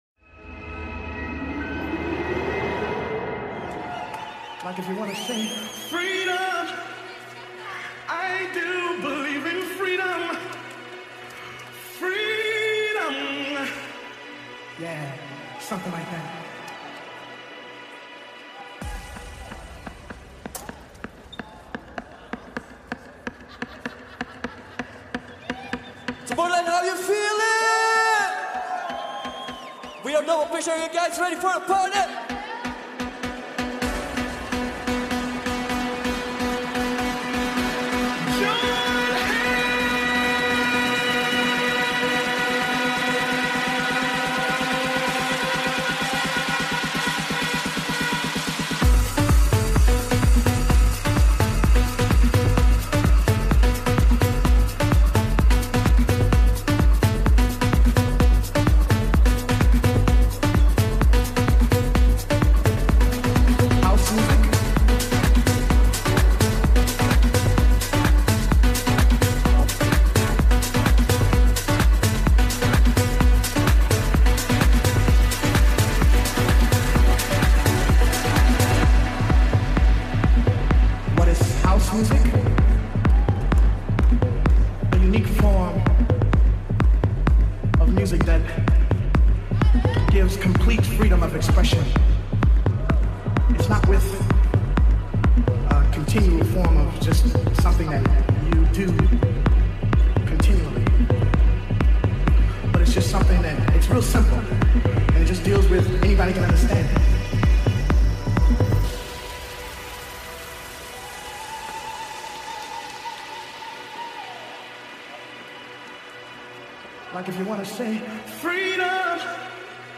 Also find other EDM Livesets,
Liveset/DJ mix